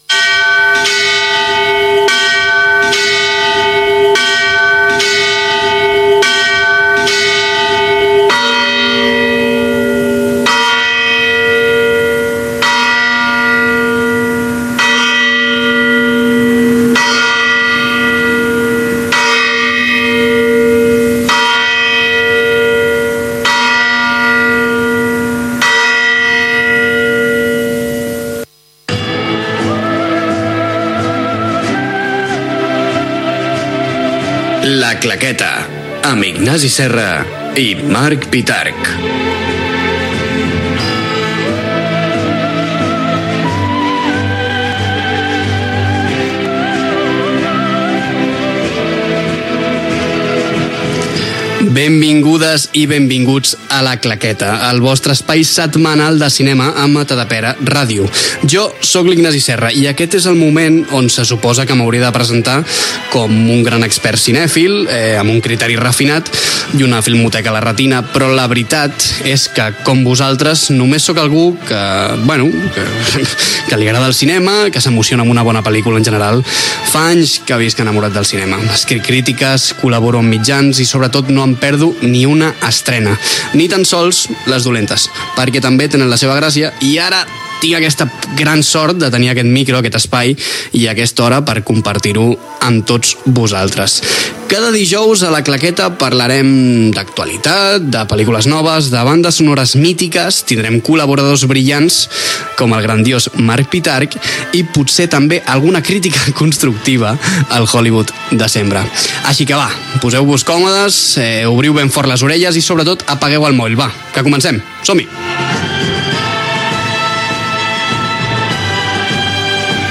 Primera edició del programa. Careta del programa, presentació, temes que s'hi tractaran, repàs a l'actualitat cinematogràfica: nova pel·lícula de la saga James Bond
FM